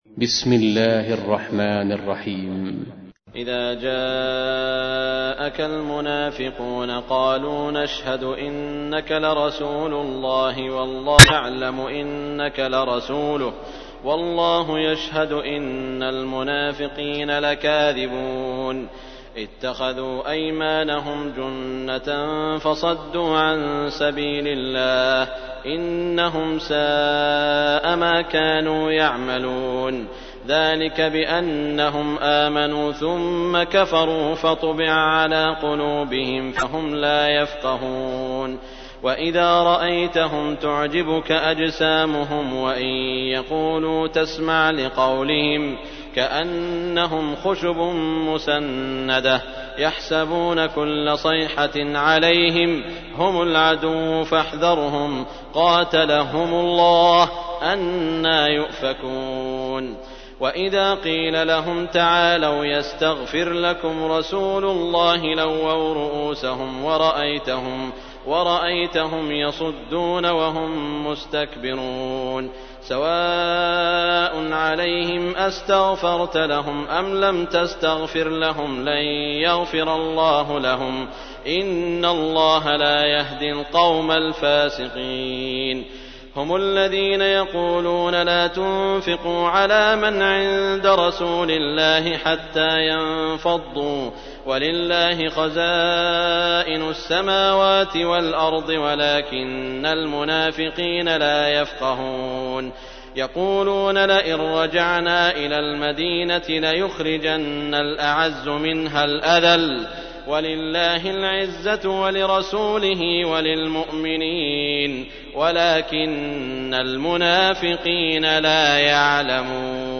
تحميل : 63. سورة المنافقون / القارئ سعود الشريم / القرآن الكريم / موقع يا حسين